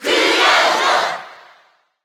Category:Crowd cheers (SSB4) You cannot overwrite this file.
Cloud_Cheer_Japanese_SSB4.ogg